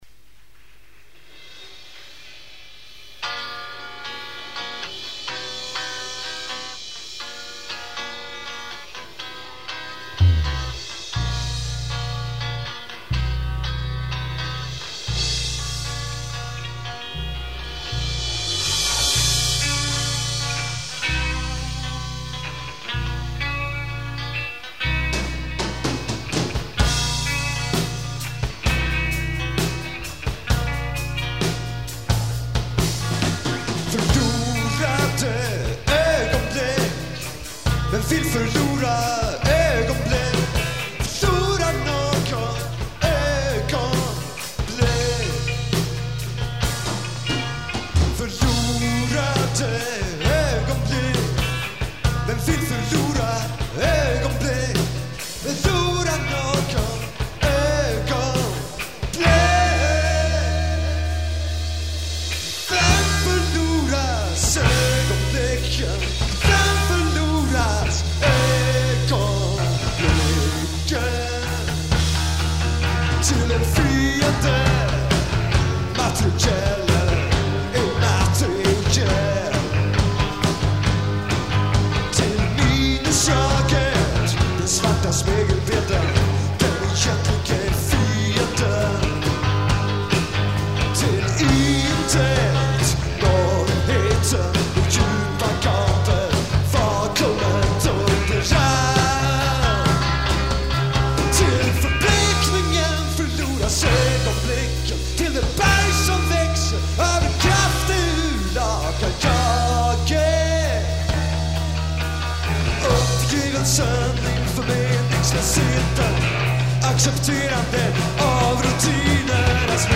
Bass
Guitar
Drums
At Arbis, November 9, 1983